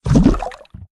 tt_s_ara_cmg_waterCoolerFill.ogg